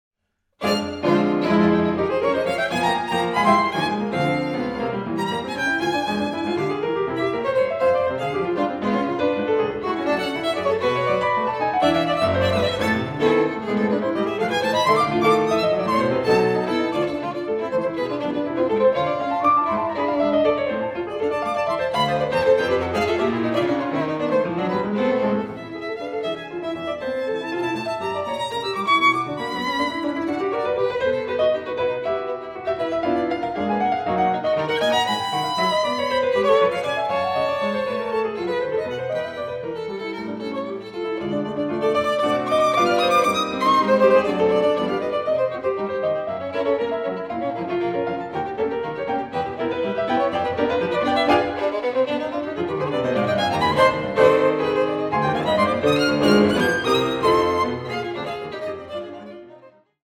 Violine
Klavier